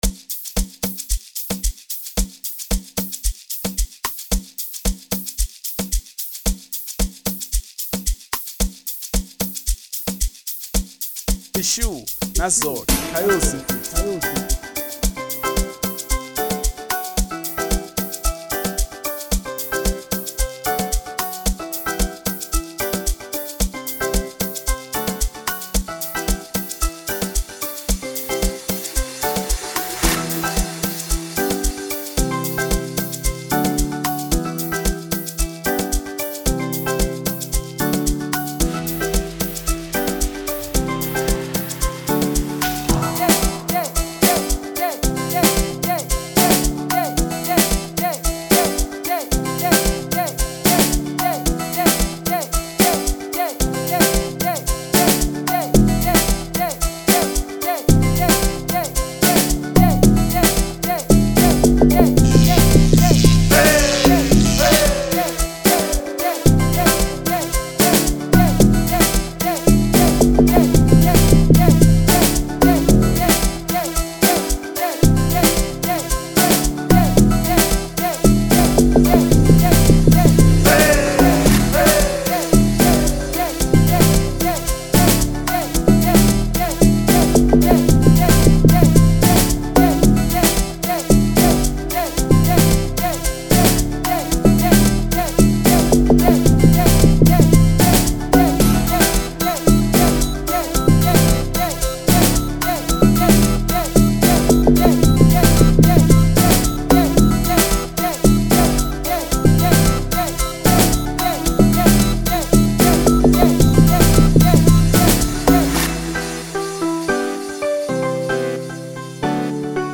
03:55 Genre : Amapiano Size